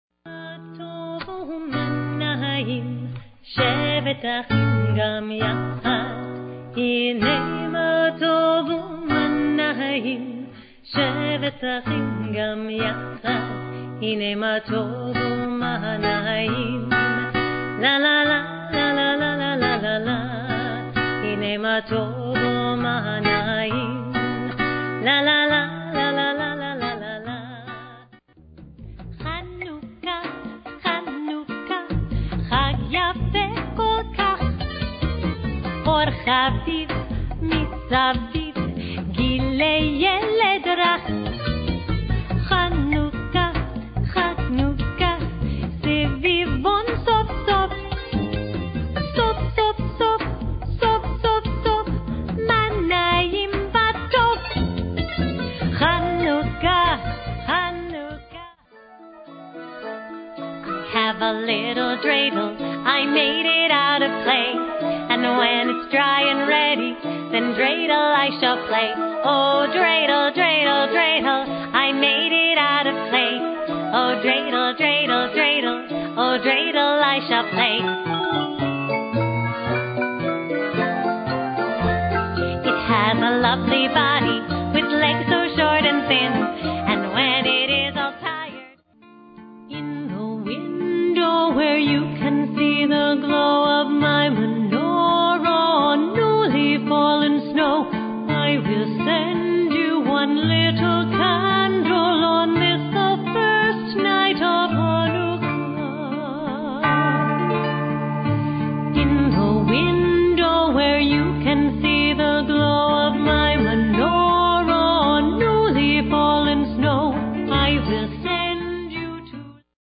Instrumental/Vocal